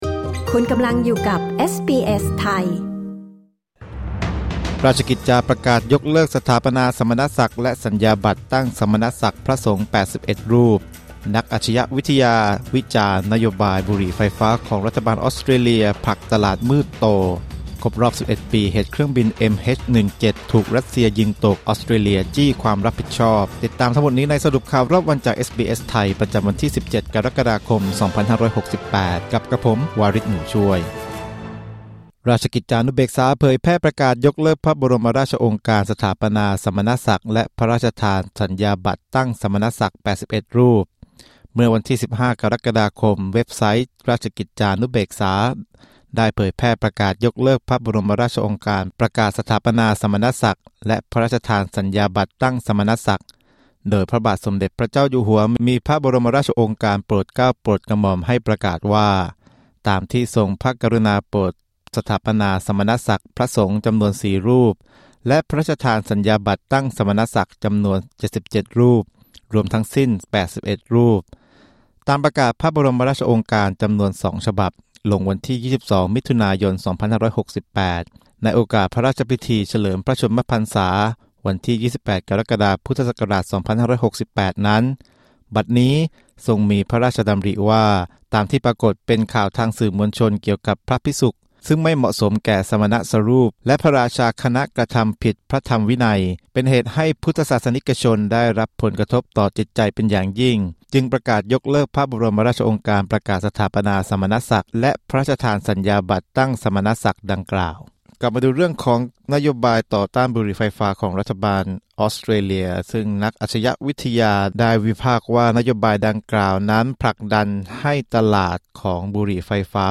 สรุปข่าวรอบวัน 17 กรกฎาคม 2568